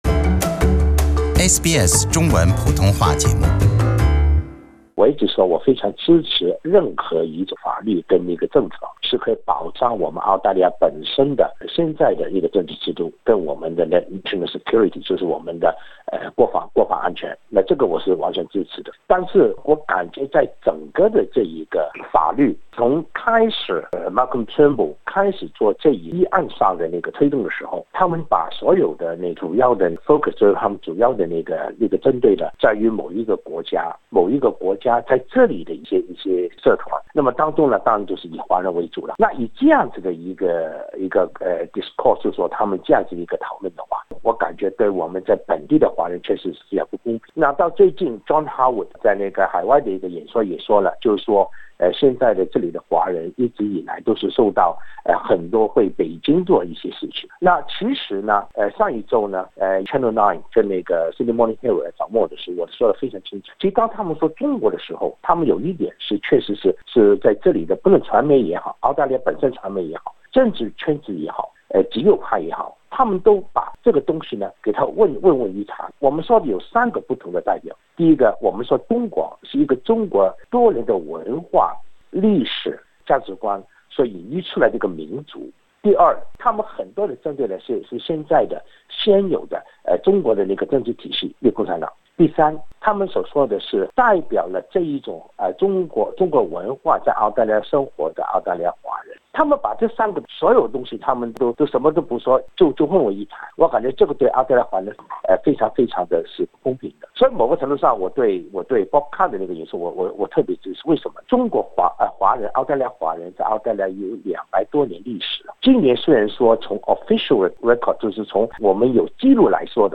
王国忠接受SBS普通话节目的采访，他表示，外国干预的定义，以及他本人被指的“被统战”都没有准确的定义，来自不同背景的华人也被混淆概念。